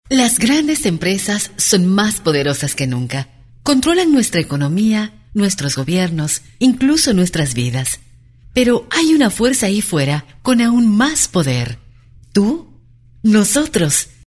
Warm, pleasant, deeply emotive, smooth and comfortable voice. Confident, energetic, professional, Corporate and Institutional.
Sprechprobe: eLearning (Muttersprache):